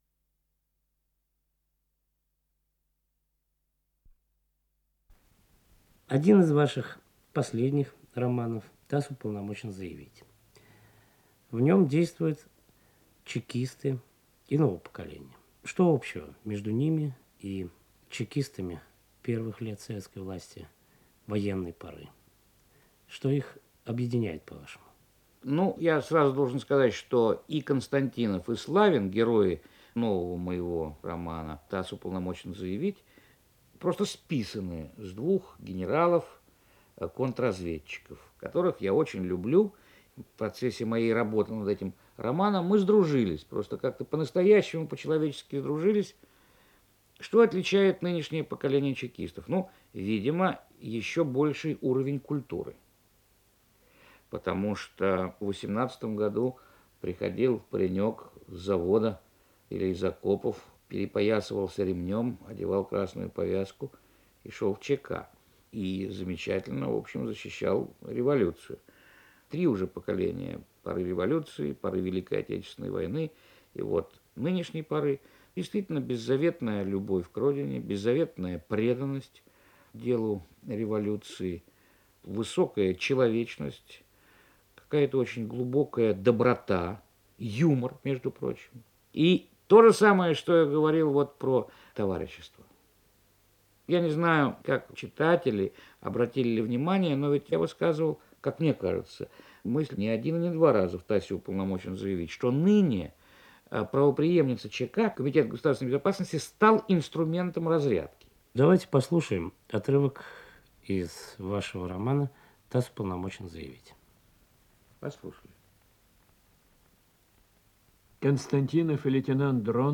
Встреча с писателем Юлианом Семёновым
Редакция Литературная